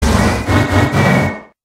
Cri_0895_EB.ogg